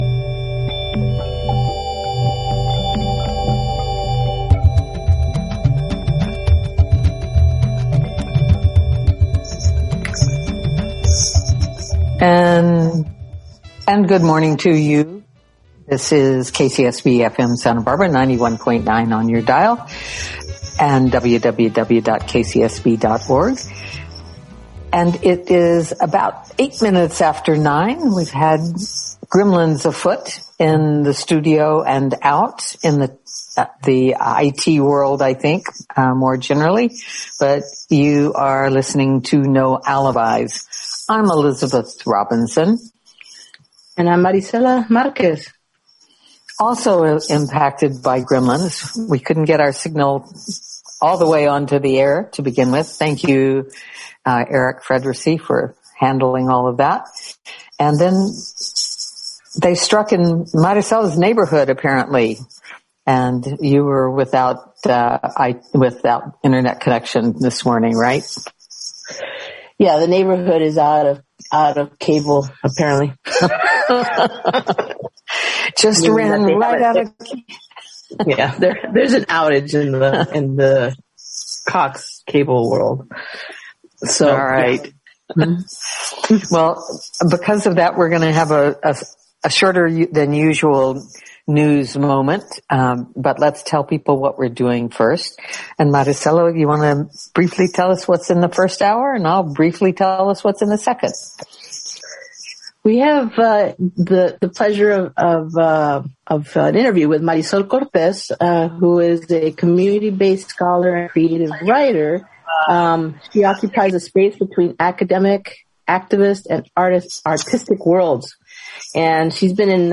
earth day-ish radio interview